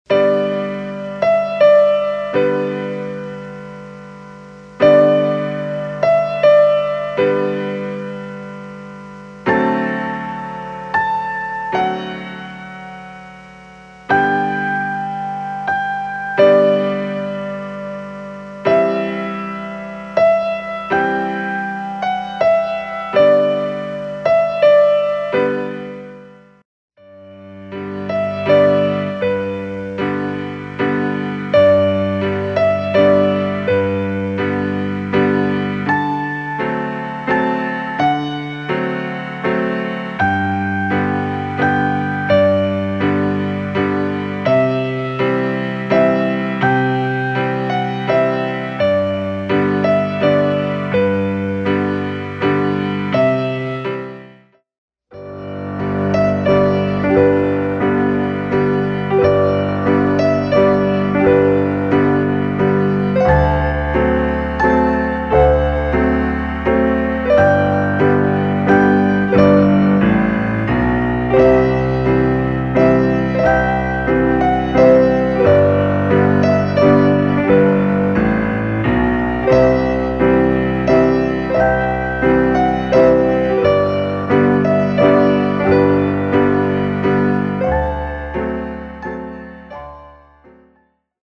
Piano Solo - Beginner/Intermediate/ Late Intermediate